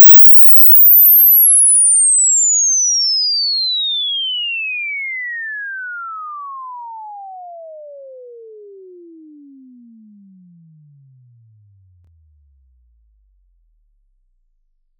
invsweep.wav